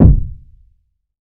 CDK Sauce kick.wav